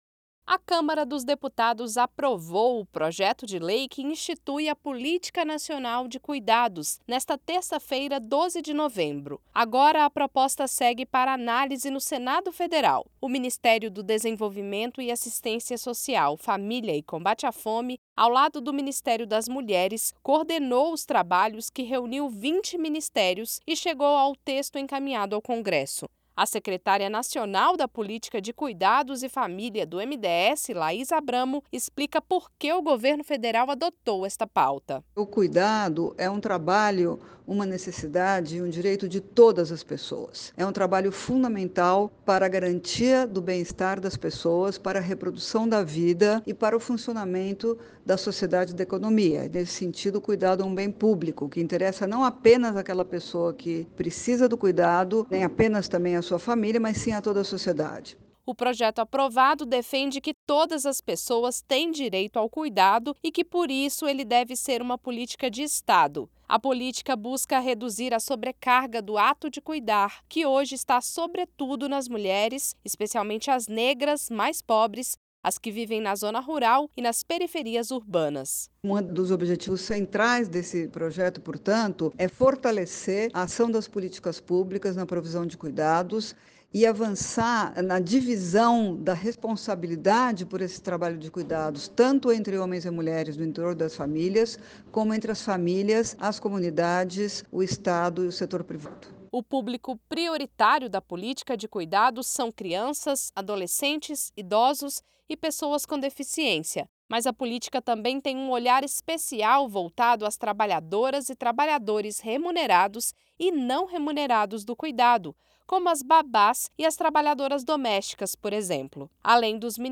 Boletim do MDS